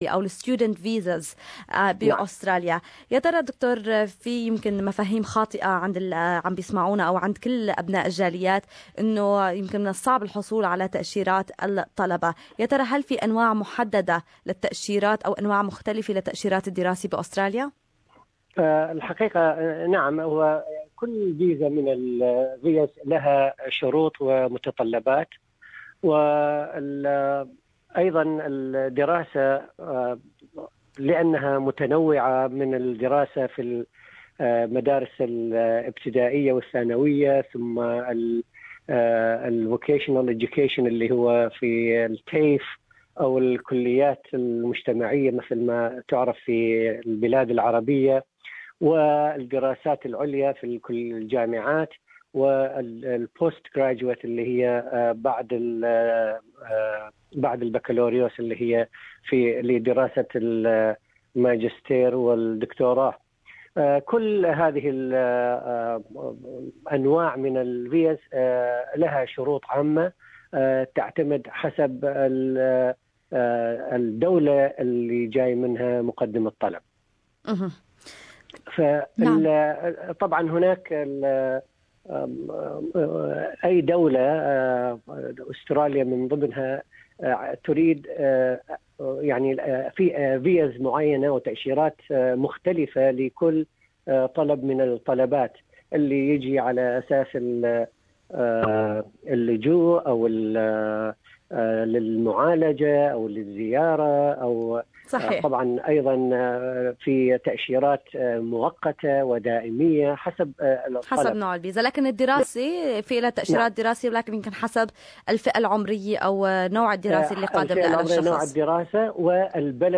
مقابلة مباشرة